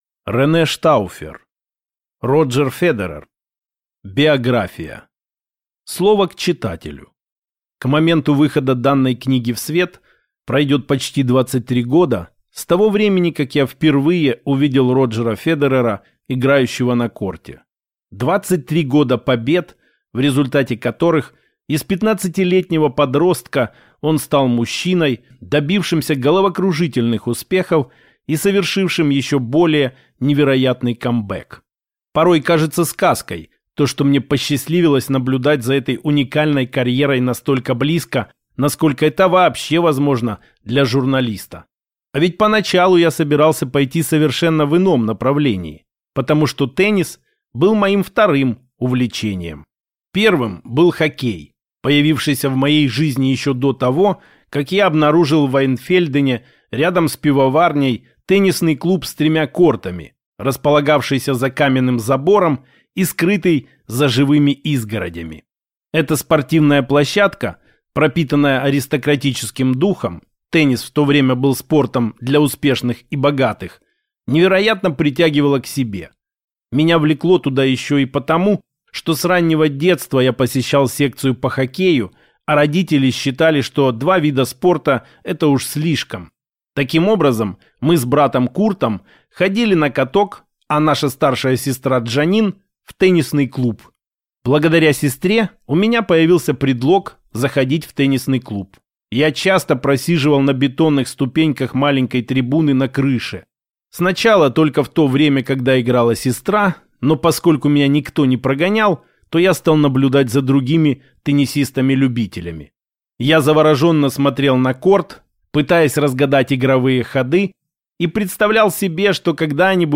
Аудиокнига Роджер Федерер: Биография | Библиотека аудиокниг